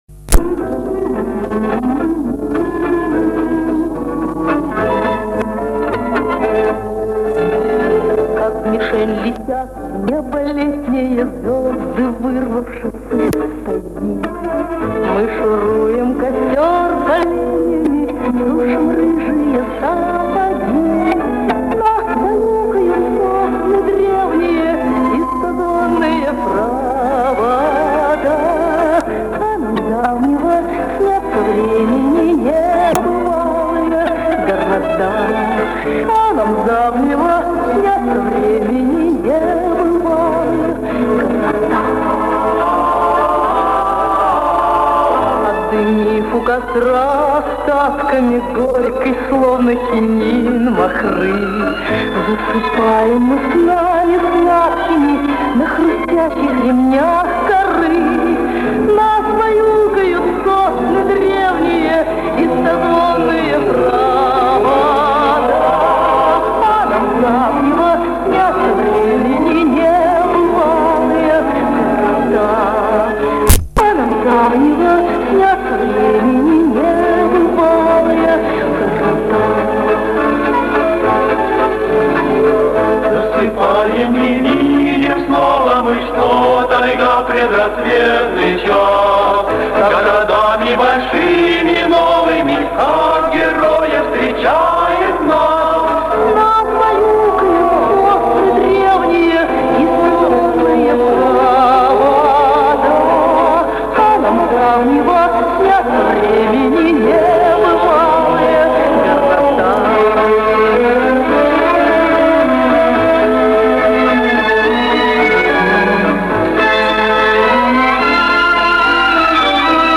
Немного громче